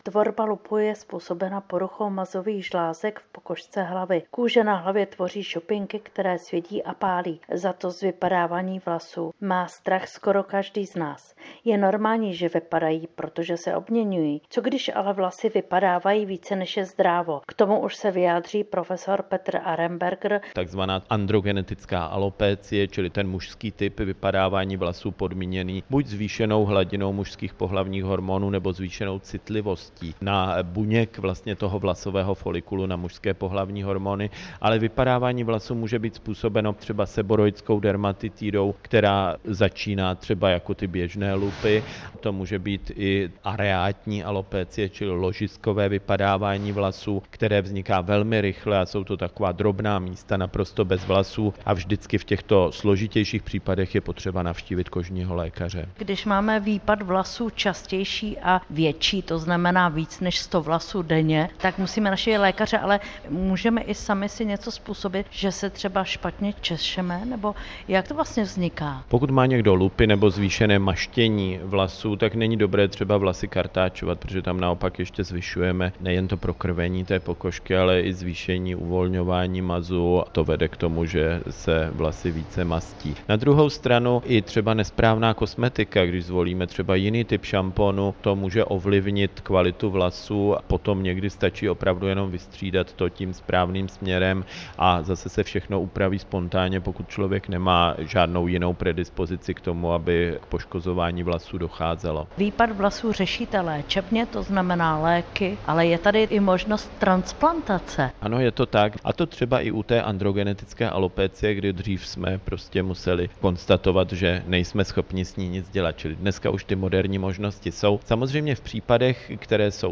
AUDIO rozhovor: Jak výpad vlasů může člověka potrápit?